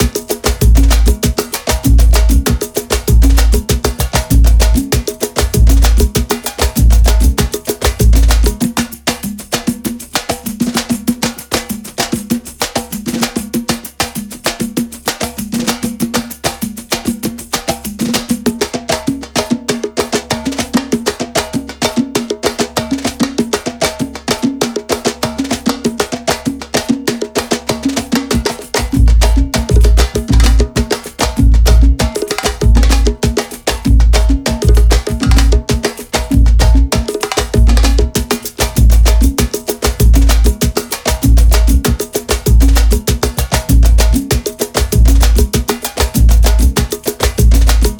Sons com o swing do É o Tchan, incluindo loops e one-shots de conga, timbal, surdo e pandeiro, perfeitos para produções dançantes.
7 Variações Rítmicas com instrumentos como Conga, Pandeiro, Timbal, Surdo e Repique Torpedo.